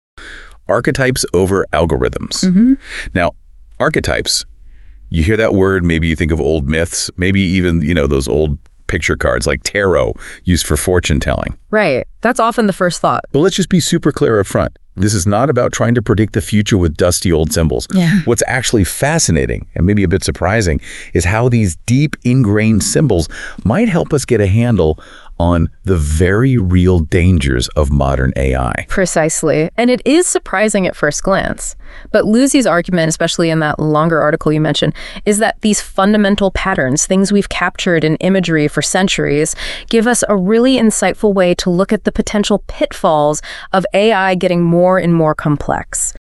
Here is a 46 second excerpt of what some of Google’s AIs are saying about this after reading my other much longer article, Archetypes Over Algorithms: How an Ancient Card Set Clarifies Modern AI Risk.